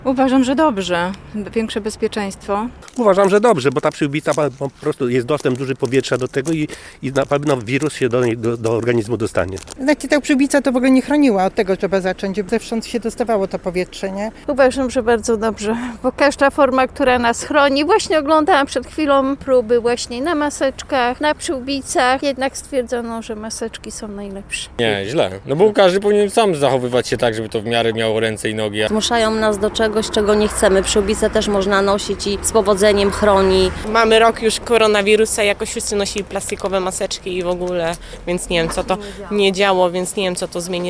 Maseczki zamiast szalika [SONDA]
Zapytaliśmy zielonogórzan, co sądzą o nowych przepisach – ich zdania są podzielone: